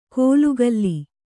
♪ kōlugalli